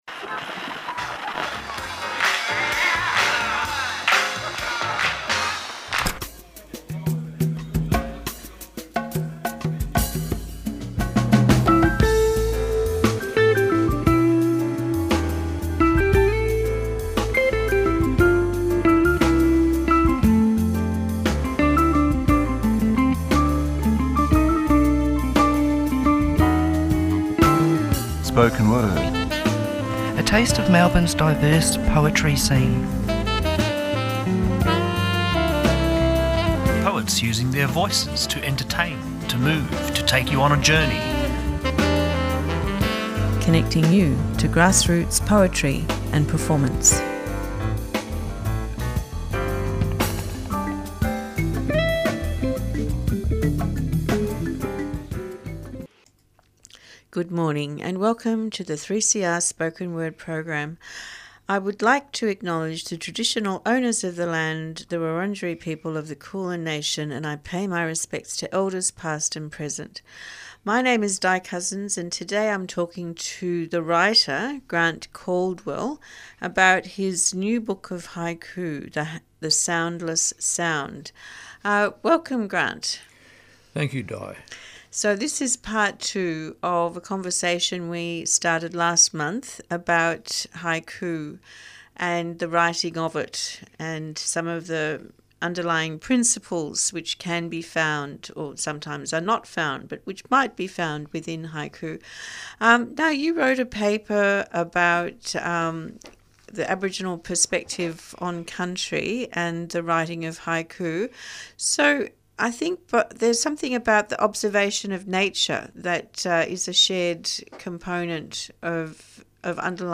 Tweet Spoken Word Thursday 9:00am to 9:30am A program dedicated to the eclectic world of poetry and performance. Guests are contemporary poets who read and discuss their works.